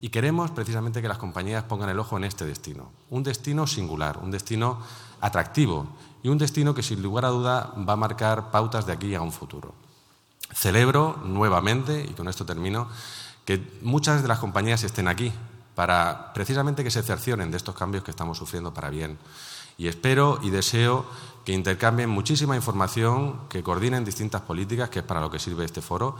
El Salón de Actos del MUREC ha acogido la jornada ‘Fly Andalucía Summit’ donde expertos del sector han analizado el potencial de ‘Costa de Almería’ como destino para todos los públicos
En la presentación intervinieron el presidente de la Diputación de Almería, José Antonio García Alcaina; la secretaria general para el Turismo de la Consejería de Turismo y Andalucía Exterior de la Junta, Yolanda de Aguilar, y el concejal de Turismo del Ayuntamiento de Almería, Joaquín Pérez de la Blanca.